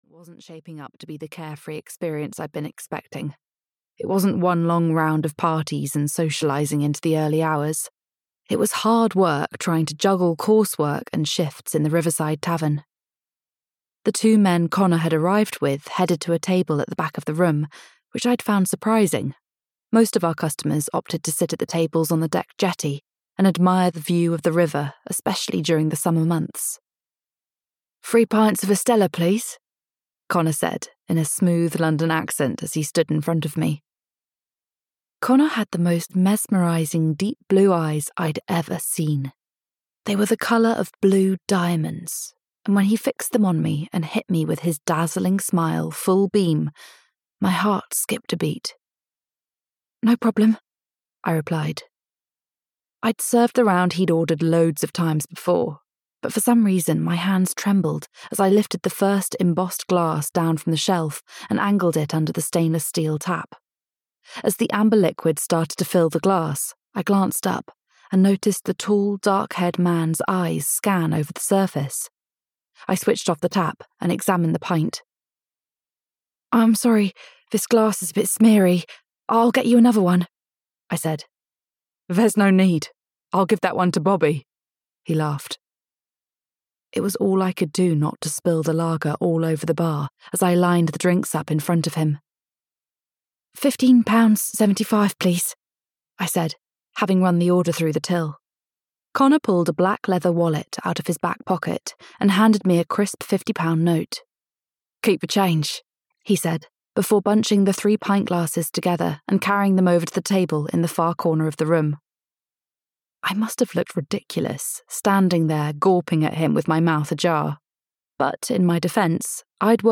Secrets and Lies (EN) audiokniha
Ukázka z knihy